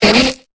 Cri de Ningale dans Pokémon Épée et Bouclier.